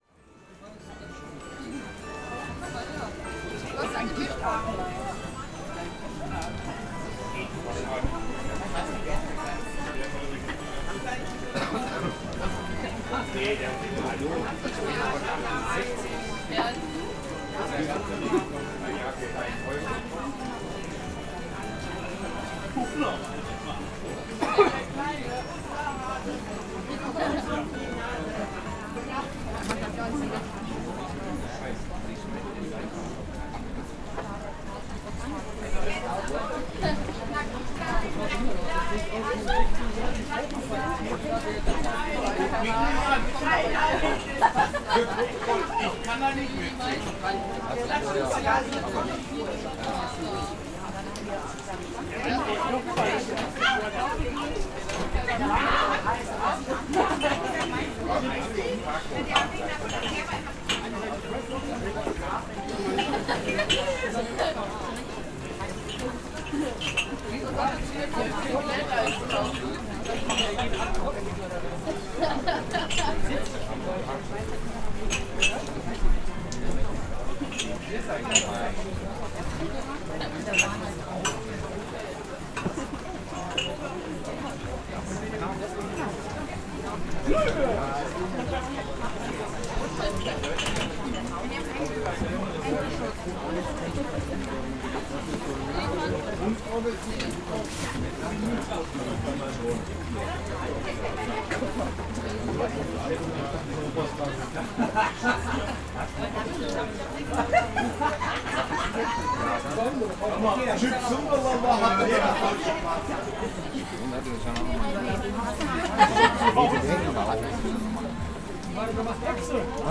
Zur Ihrer Information haben wir Ihnen die Soundaufnahme vom Weihnachtsmarkt im "Centro" Oberhausen (s. Online-Version dieser Pressemitteilung - über den Link oberhalb dieser Mail erreichbar) angehängt.
Anlage 1: Weihnachtsmarkt CentrO Oberhausen - Glühweinstand im Regen.mp3